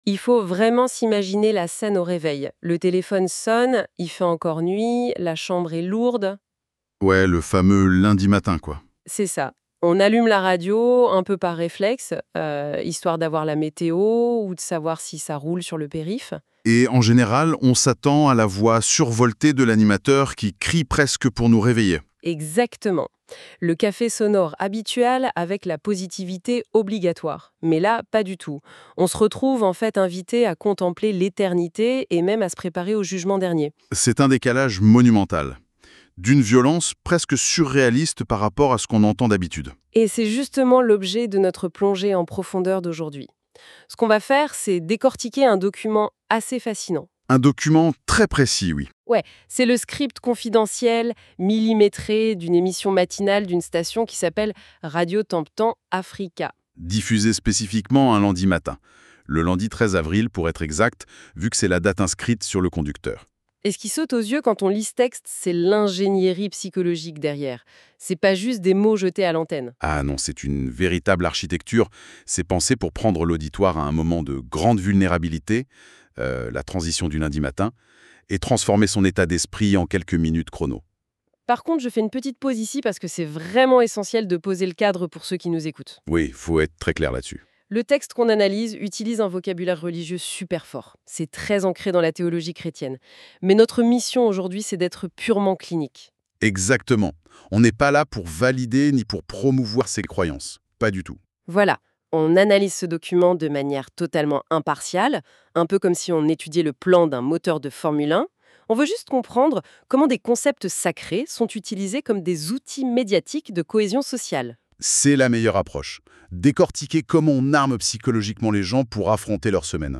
PRIÈRE Voix posée, sacrée, incarnée Bonjour, chers abonnés et invités.